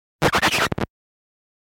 На этой странице собраны аутентичные звуки виниловых пластинок: характерные потрескивания, теплый аналоговый звук и шумы, создающие особую атмосферу.
Звук скрипа пластинки от касания иглы рукой